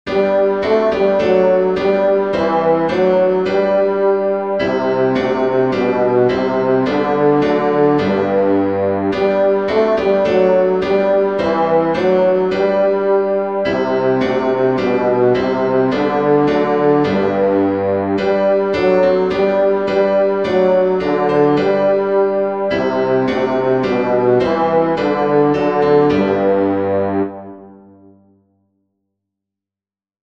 Bass
dix_as_with_gladness_men_of_old-bass.mp3